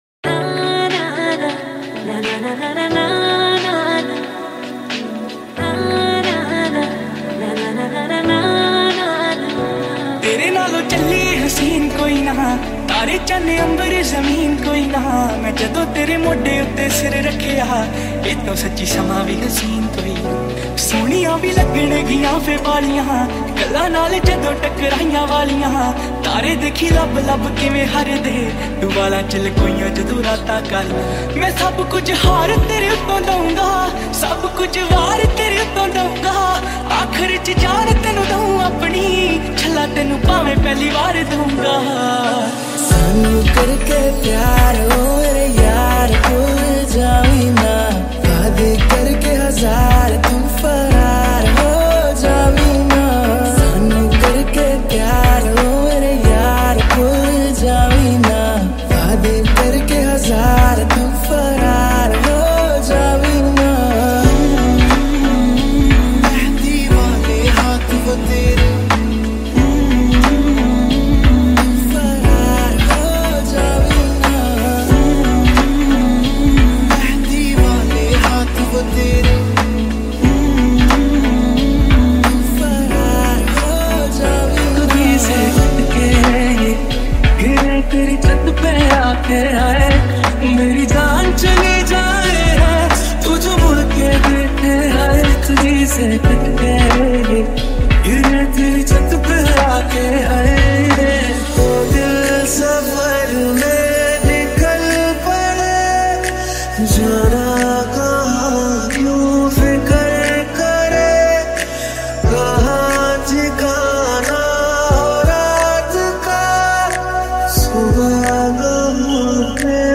High quality Sri Lankan remix MP3 (3.2).
remix